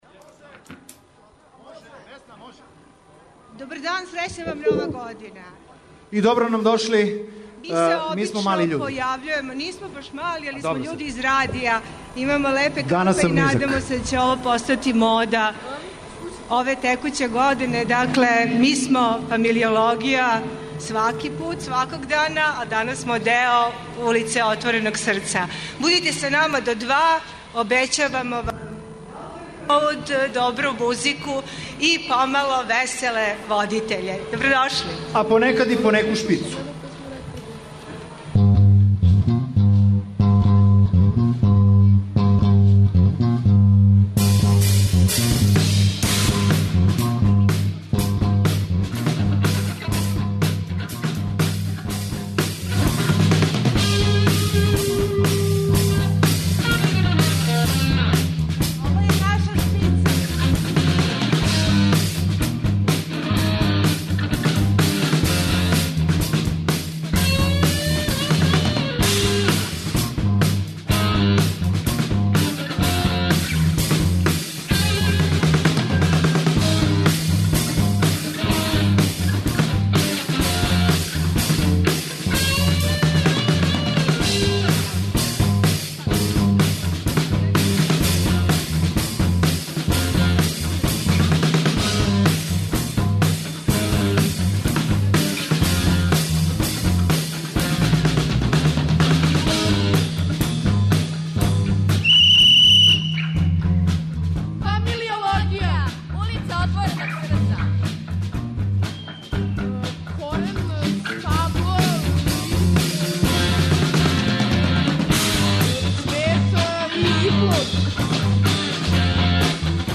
А ова наша прича, прва у Новој 2016. години емитује се из Улице отвореног срца - испред Атељеа 212.